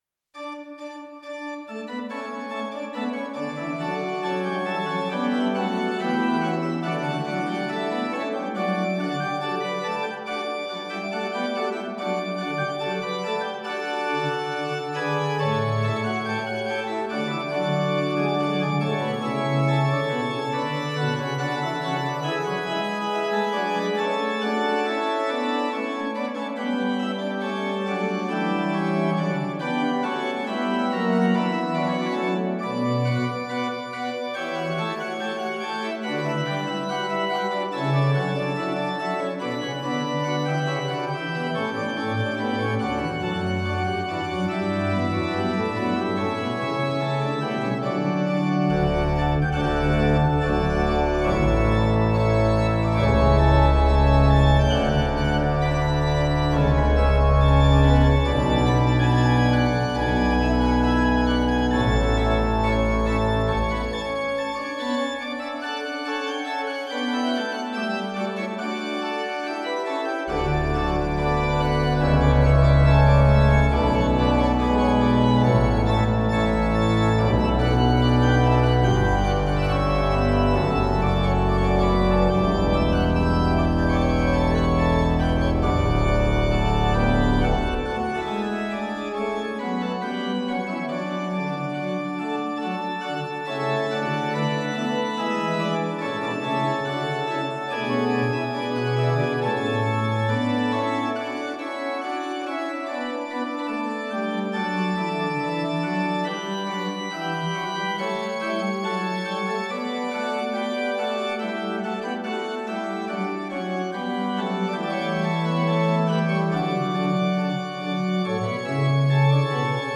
This setting for organ was composed by Johann Gottfried Walther (1684 –1748), who was a German music theorist, organist, composer, and lexicographer of the Baroque era.